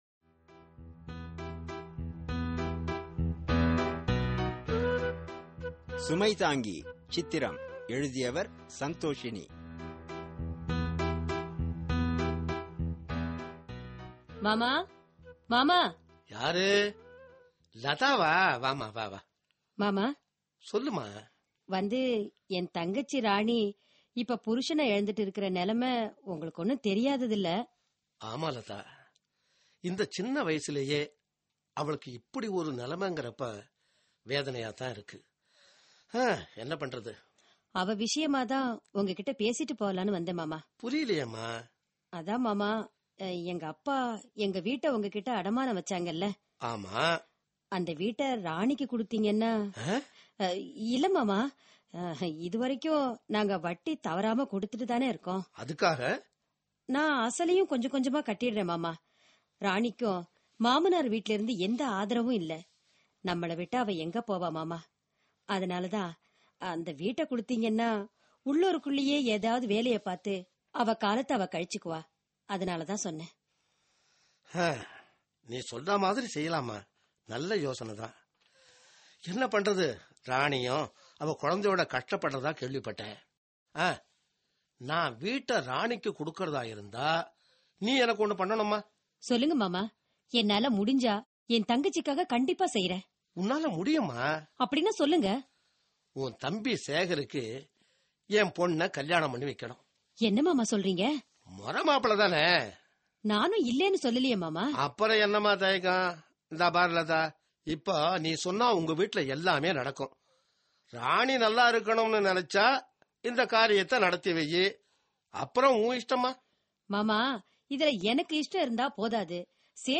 Social Drama